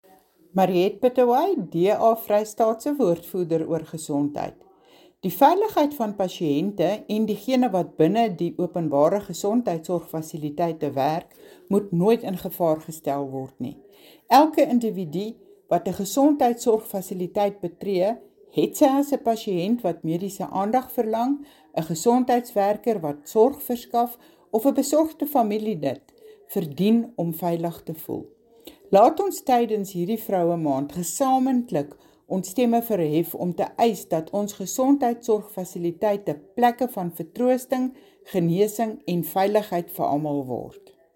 Afrikaans soundbites by Mariette Pittaway MPL and